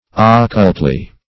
occultly - definition of occultly - synonyms, pronunciation, spelling from Free Dictionary Search Result for " occultly" : The Collaborative International Dictionary of English v.0.48: Occultly \Oc*cult"ly\, adv.